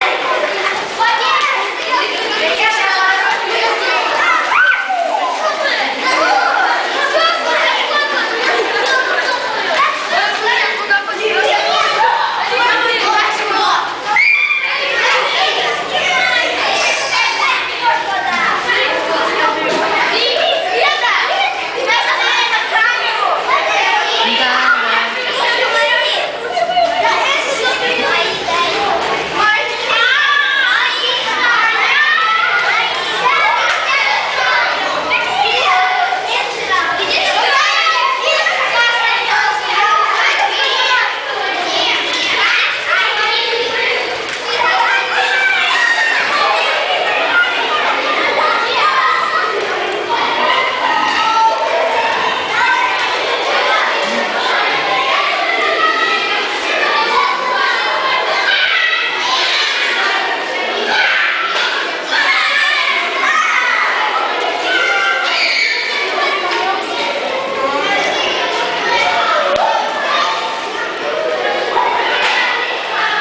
School № 63 -Corridors - School Sounds - (256 kbps)
• Category: School Break 1301